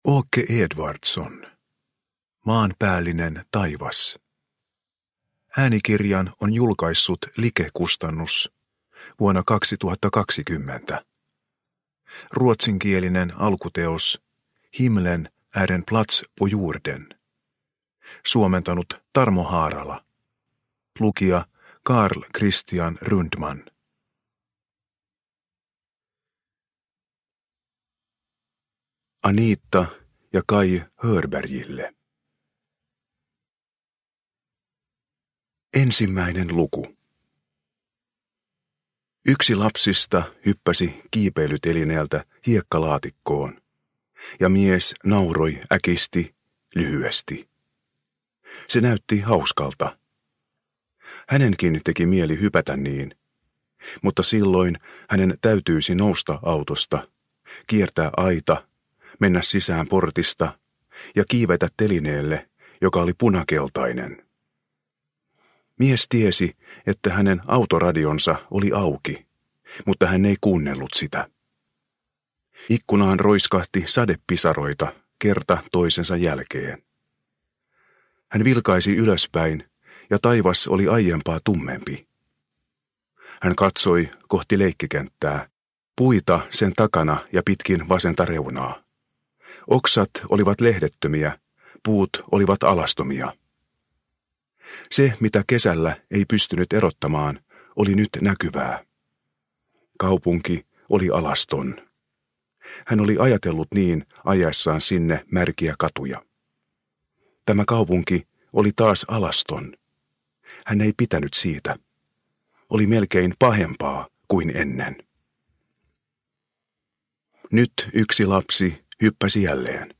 Maanpäällinen taivas – Ljudbok – Laddas ner